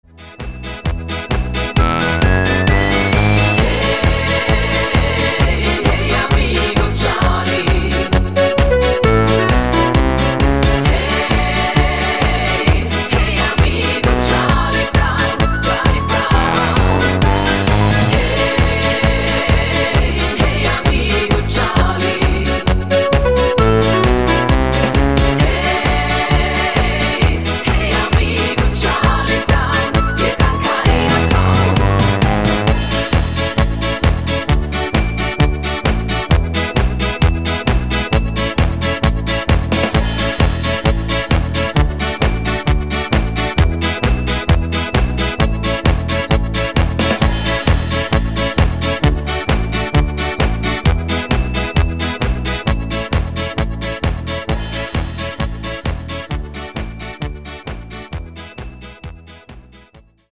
neue fetzige Version auf deutsch